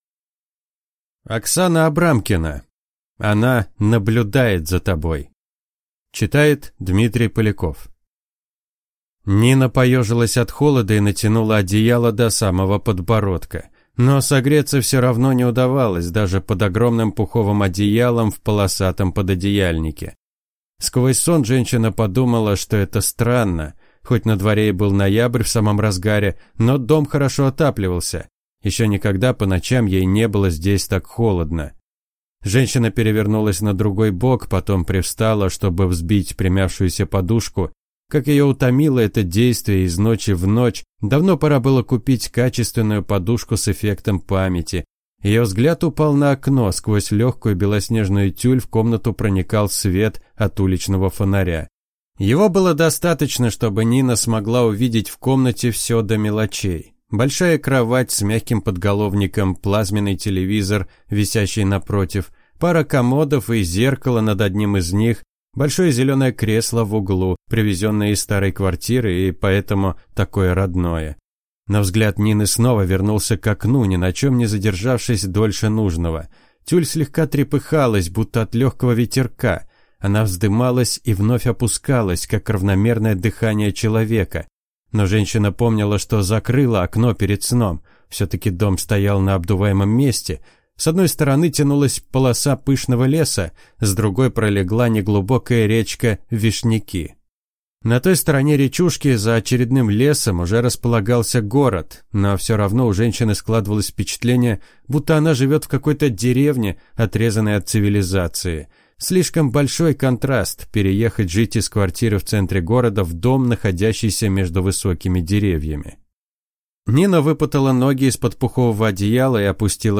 Аудиокнига Она наблюдает за тобой | Библиотека аудиокниг